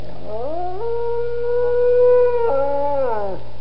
Howling Sound Effect
Download a high-quality howling sound effect.
howling.mp3